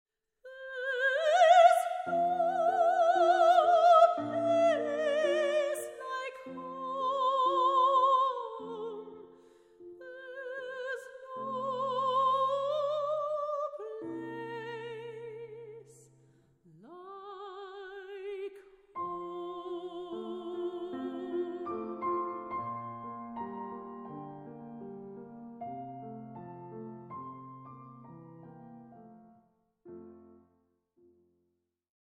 ravishing Australian soprano
Soprano
Piano